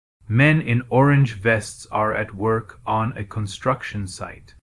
Text-to-Speech
Add clones